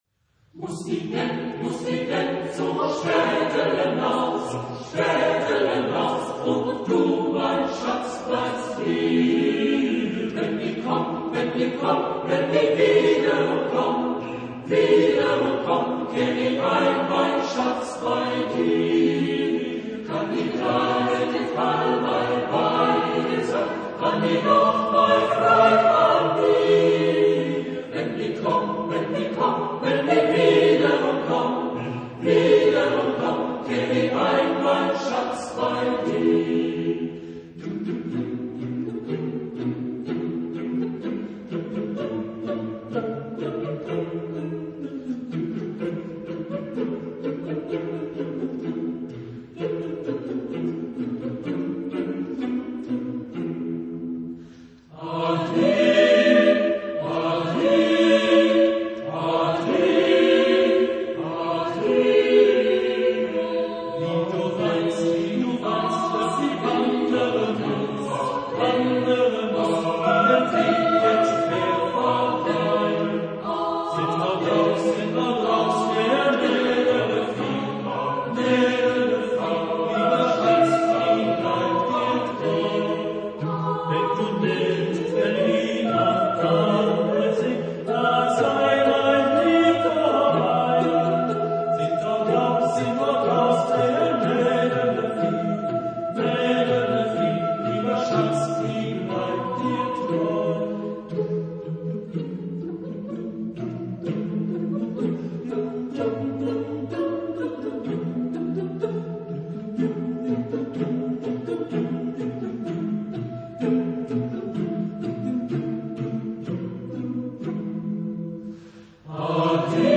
Genre-Style-Forme : Folklore ; Chanson ; Profane
Type de choeur : SATB  (4 voix mixtes )
Tonalité : mi majeur
Origine : Souabe